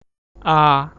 {a}/{aa} आ <))